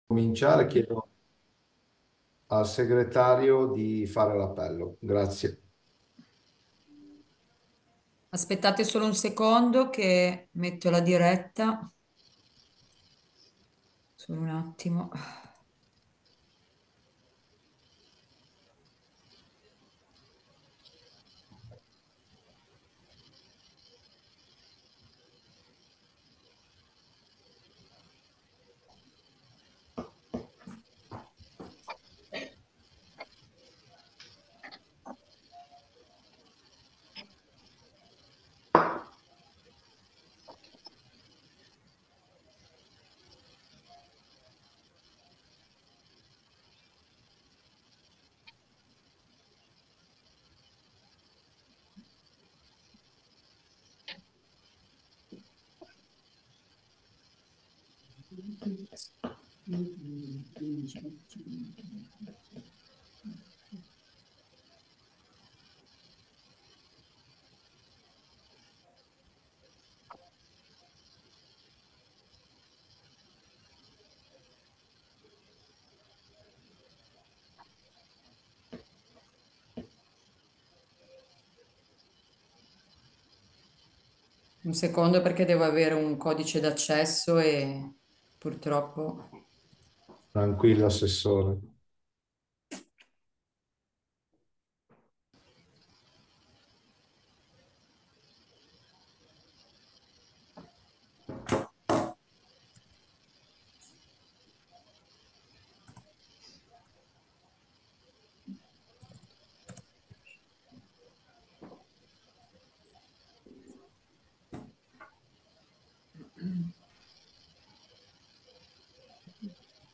Seduta del Consiglio comunale mercoledì 28 maggio 2025, alle 21.00, in videoconferenza.